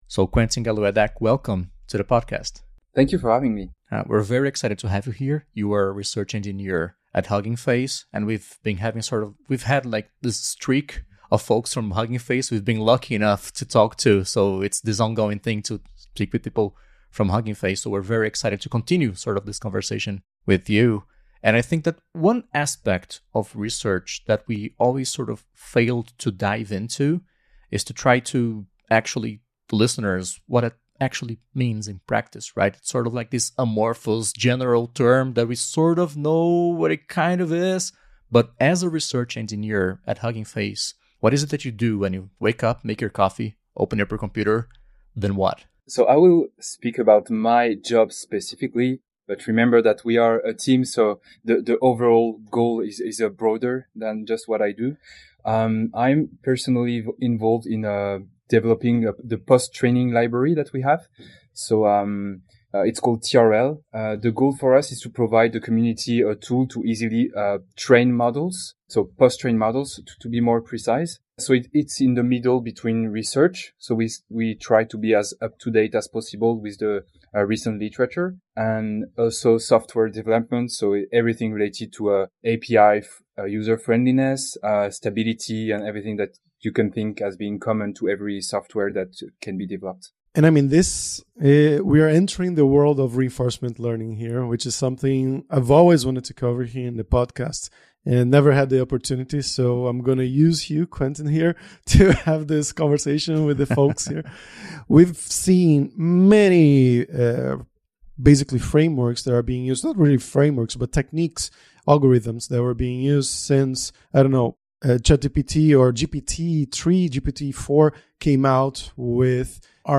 papo